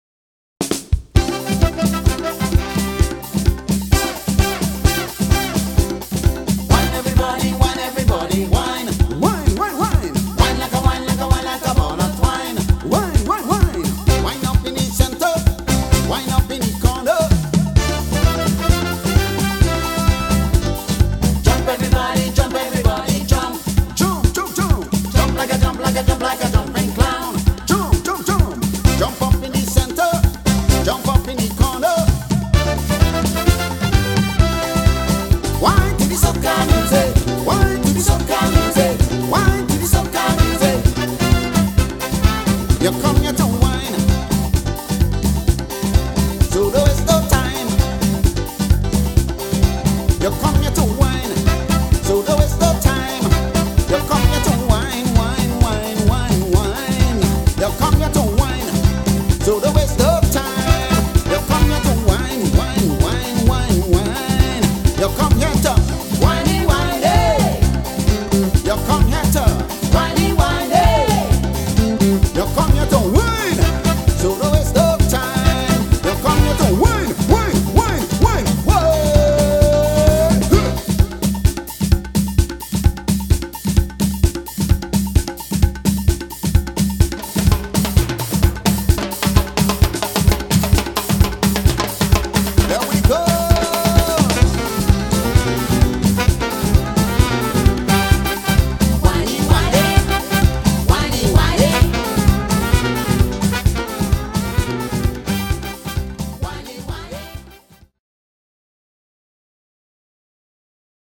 TThe Trio: Bass, Guitar & Drums, self-contained vocals.
Soca, Calypso, Reggae, Compa, Zouk and African music.